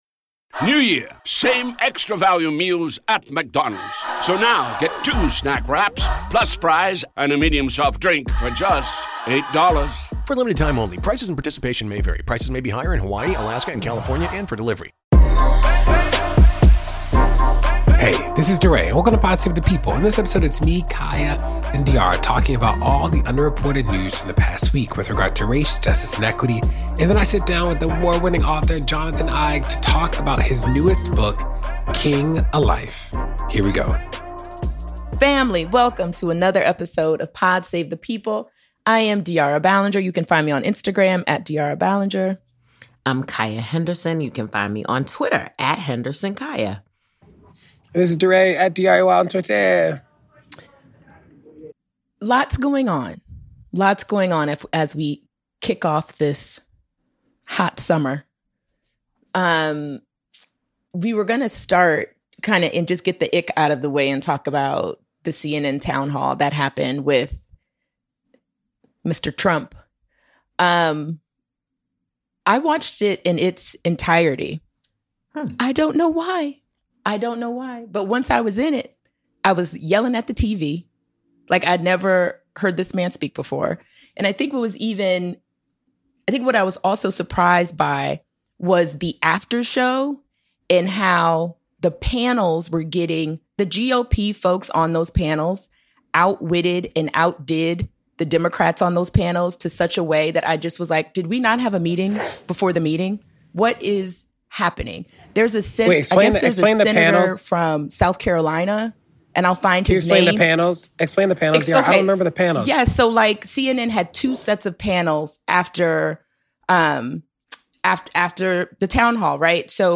DeRay interviews award-winning author Jonathan Eig about his new book King: A Life.